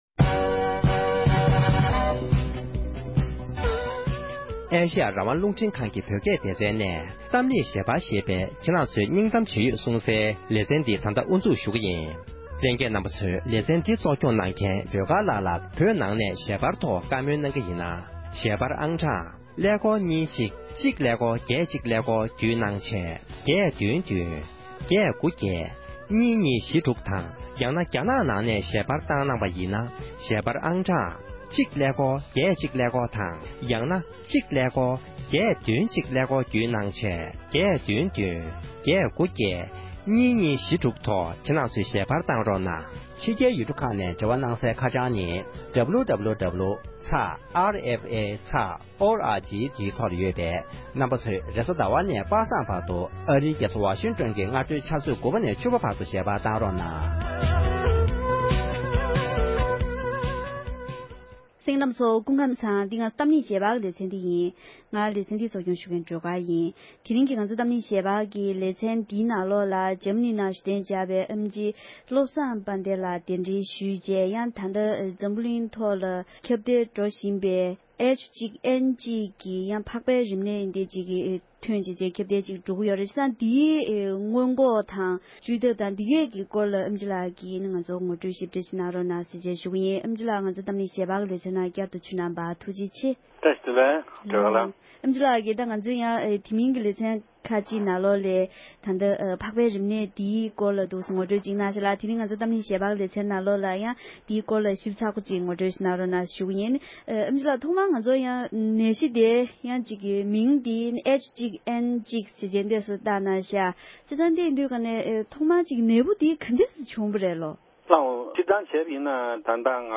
འཛམ་གླིང་ནང་ཁྱབ་འཕེལ་འགྲོ་བཞིན་པའི་རིམས་ནད་ཐོག་གི་བགྲོ་གླེང༌།